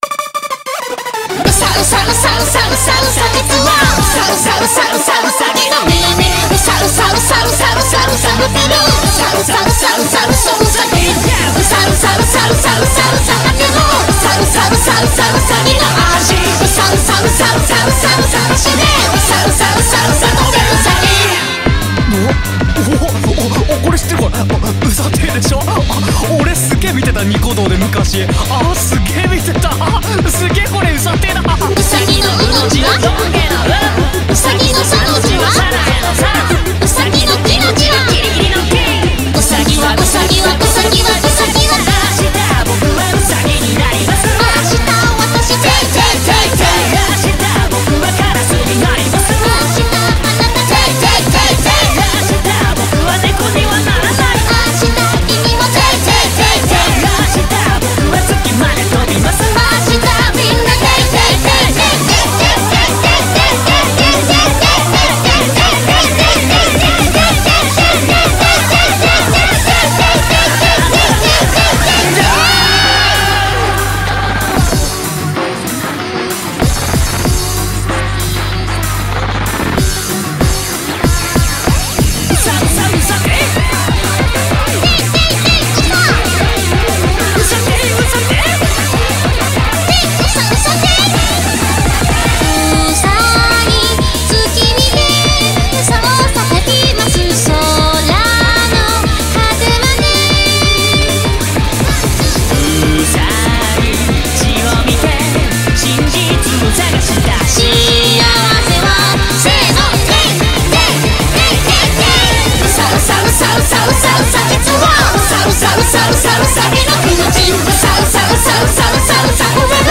BPM190
Audio QualityPerfect (High Quality)
Comments[TOUHOU DEMPA]